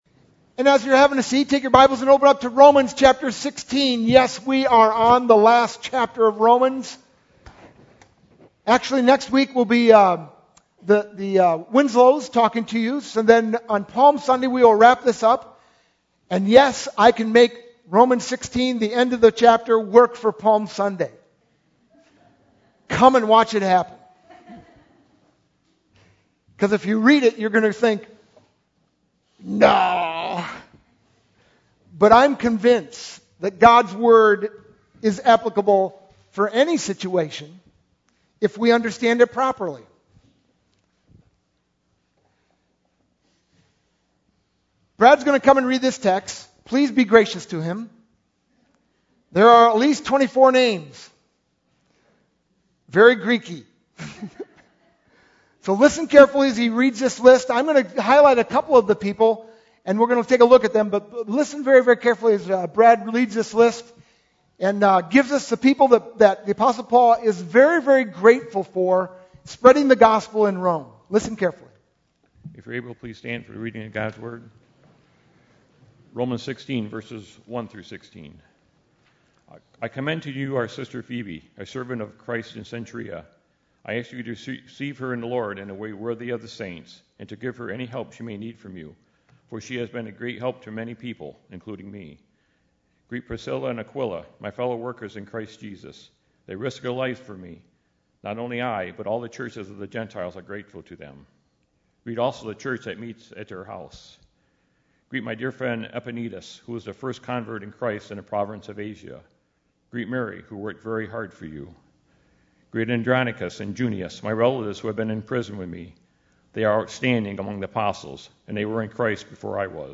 sermon-3-18-12.mp3